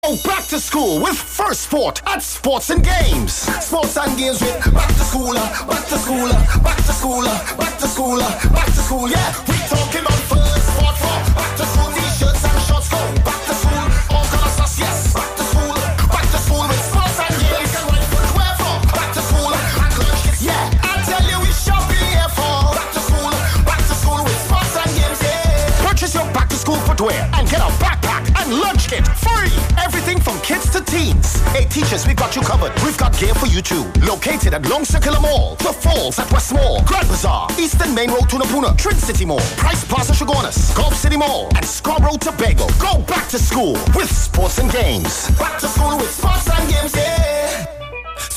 Top 5 Radio Ads
jingle
Soca